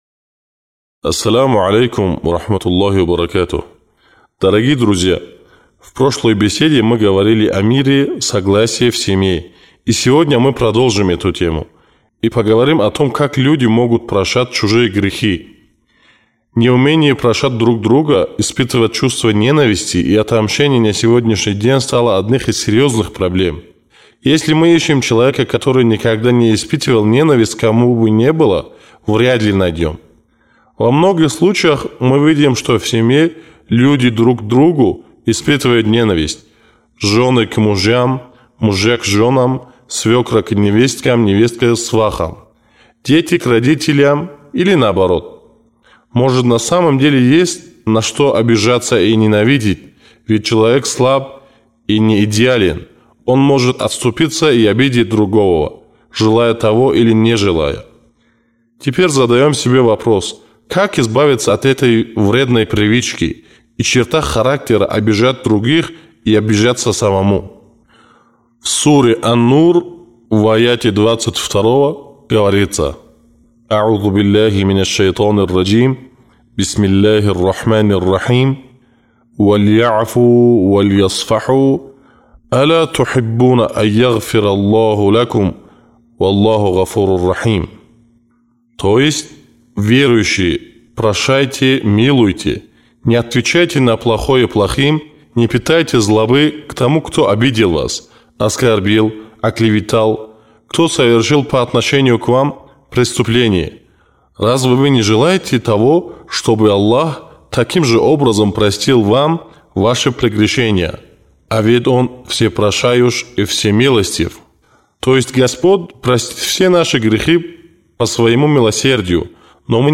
Если мы действительно хотим, чтобы другие прощали нас, тогда нам нужно научиться прощать других. Послушайте восьмую речь «Прощение», чтобы вы могли научиться прощать грехи других и то же самое находить прощение в своих грехов.